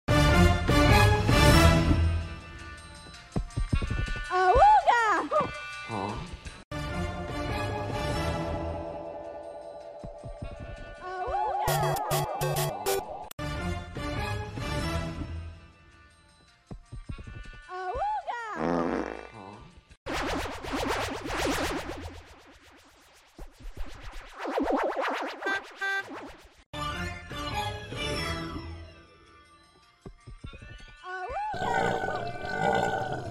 Turning Red Mei 5 Awooga sound effects free download
Turning Red Mei 5 Awooga Sound Variations in 27 Seconds